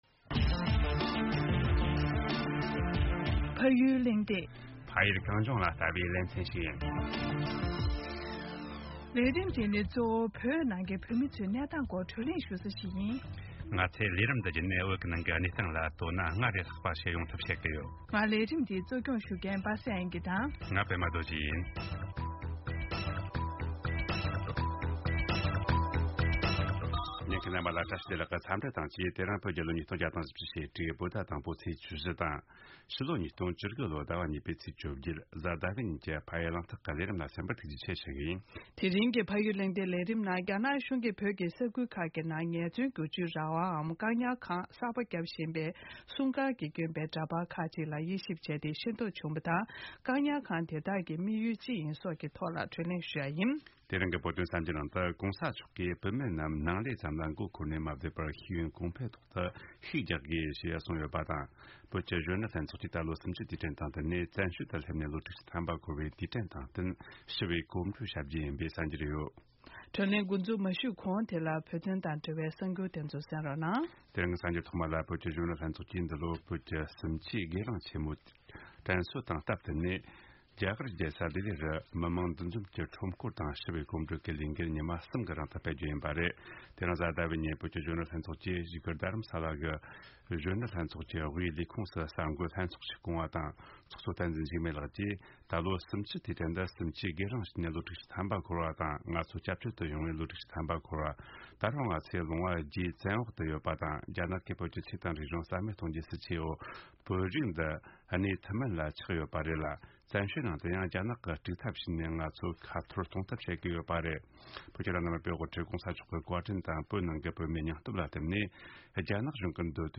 བཀག་སྐྱིལ་ཁང་དེ་དག་གི་དམིགས་ཡུལ་ཅི་ཡིན་སོགས་ཀྱི་ཐད་དུ་བགྲོ་གླེང་ཞུ་རྒྱུ་ཡིན།།